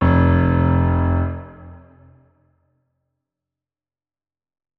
328d67128d Divergent / mods / Hideout Furniture / gamedata / sounds / interface / keyboard / piano / notes-05.ogg 47 KiB (Stored with Git LFS) Raw History